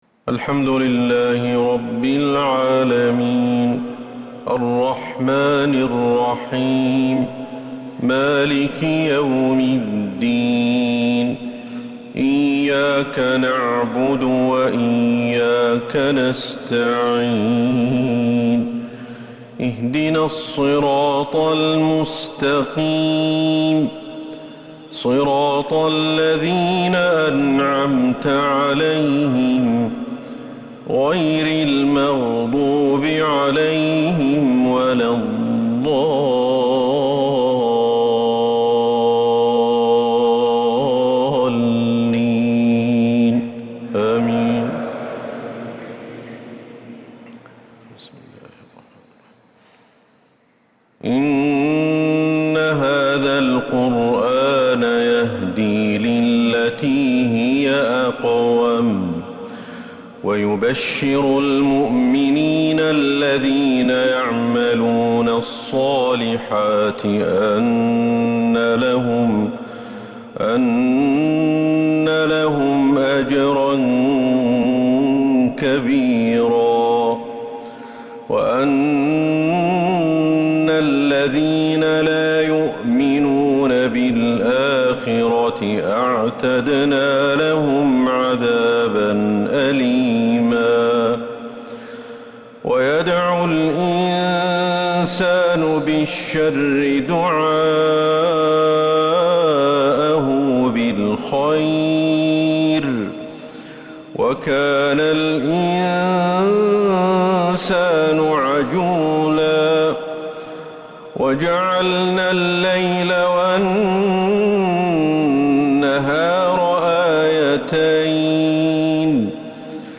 عشاء الإثنين 16 ربيع الأول 1447هـ من سورة الإسراء 9-21 | lsha prayer from Surah Al-Israa 8-9-2025 > 1447 🕌 > الفروض - تلاوات الحرمين